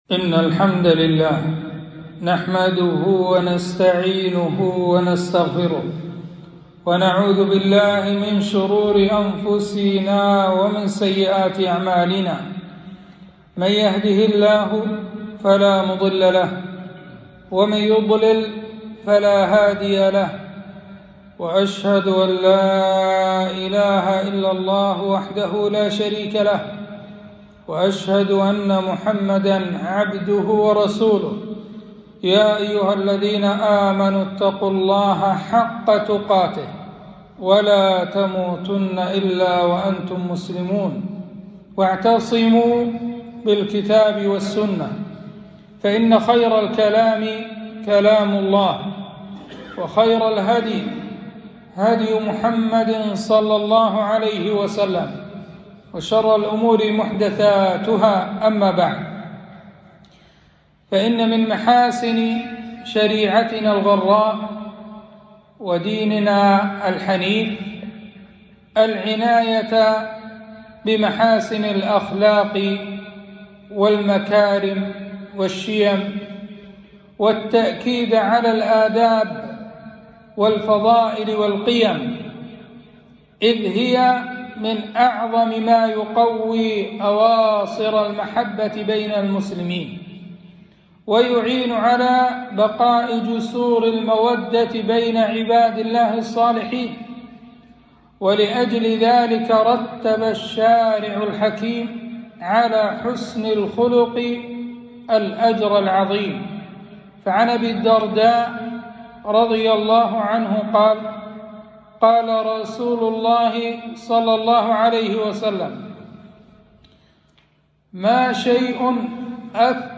خطبة - كف الأذى عن الناس صدقة